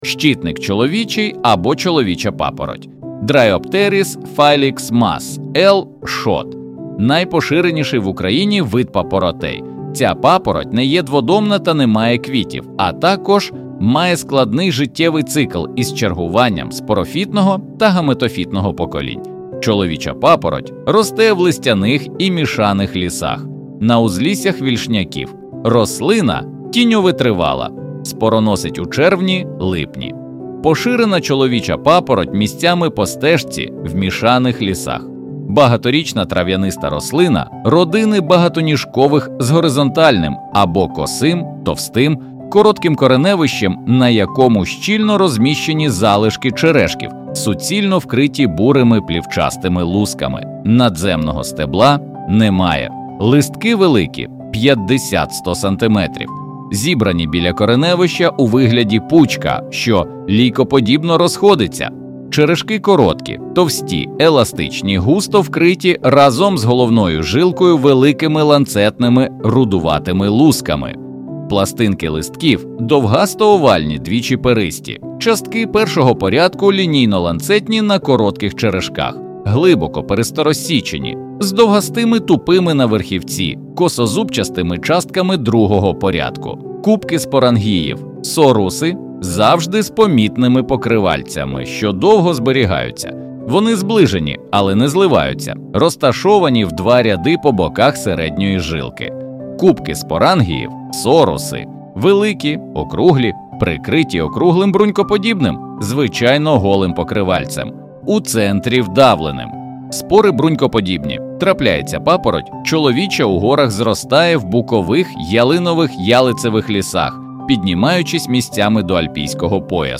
Аудіогід